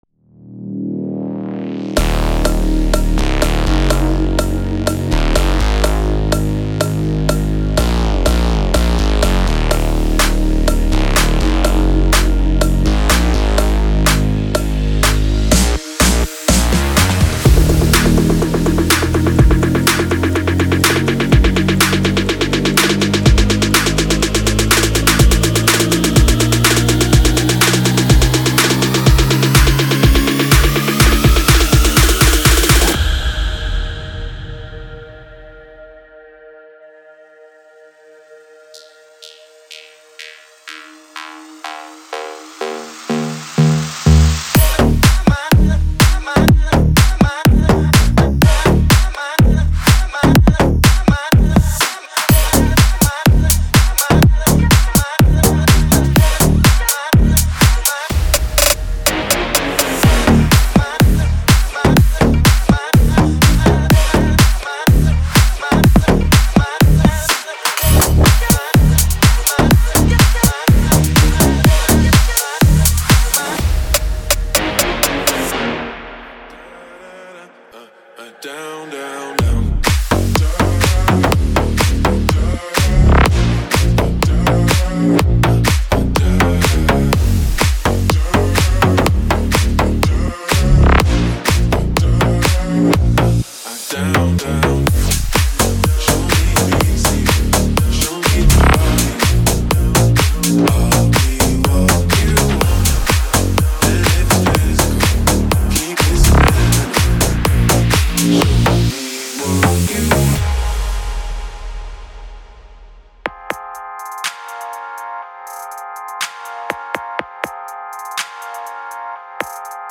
Brazilian Bass Samples:
• (Kicks,Claps,Snares,Toms,Cymbals,Percs)